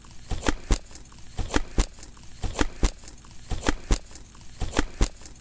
shovel_soil.ogg